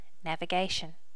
Initial check in of the sounds for the notify plugin.
navigation.wav